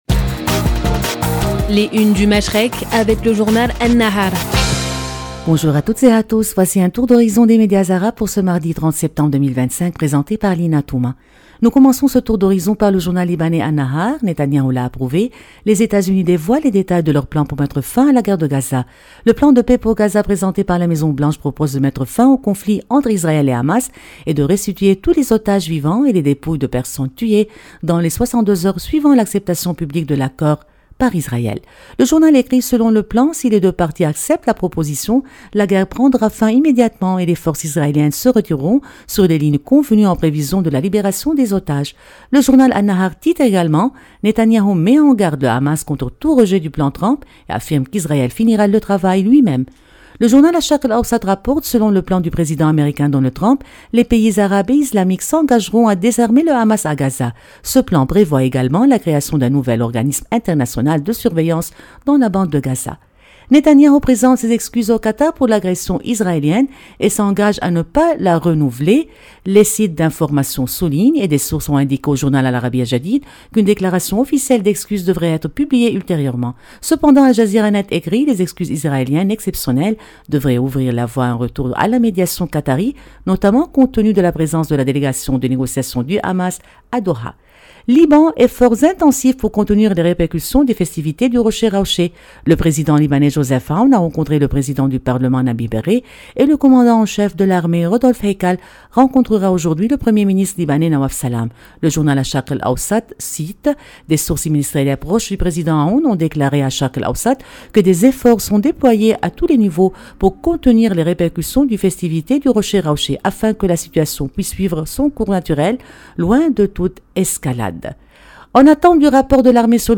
Revue de presse des médias arabes du 30 septembre 2025